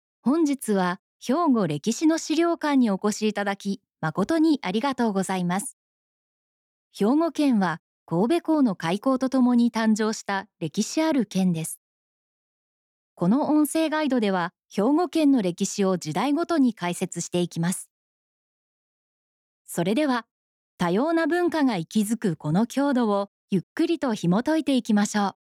やわらかさ、透明感、温かみのある声です。
落ち着いた